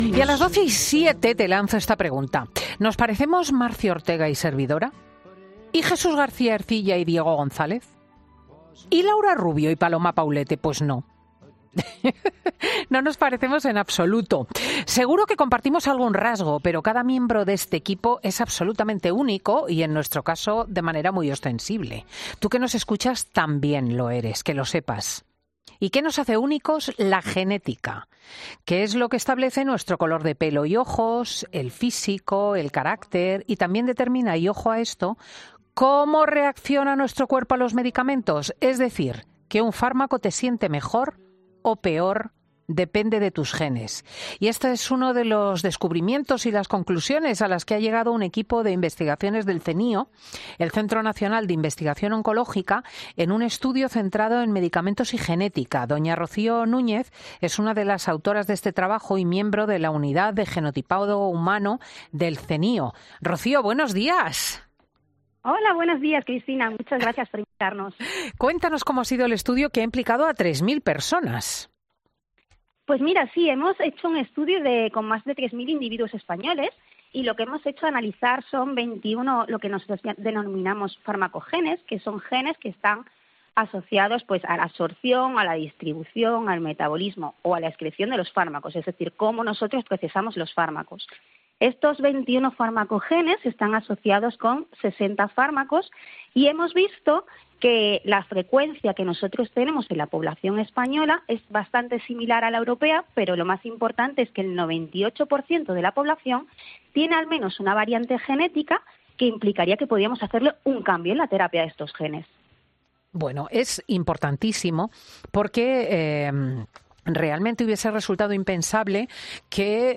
pasa este domingo por los micrófonos de 'Fin de Semana'.